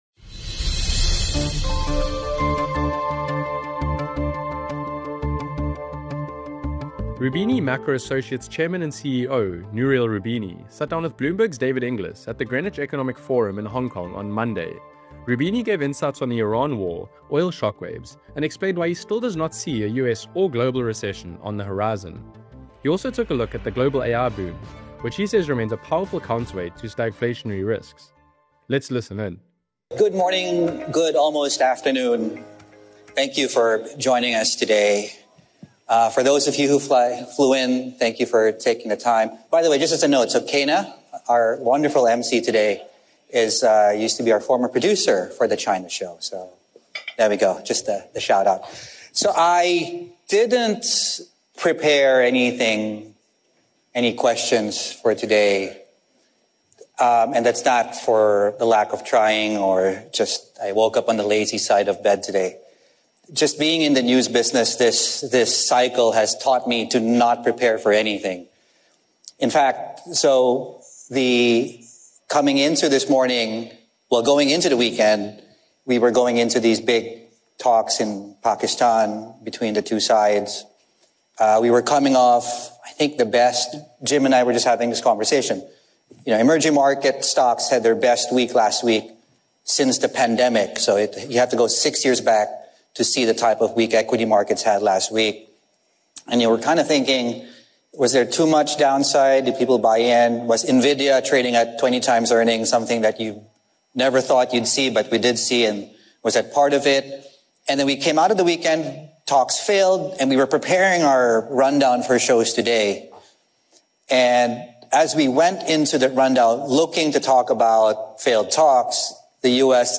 at the Greenwich Economic Forum in Hong Kong.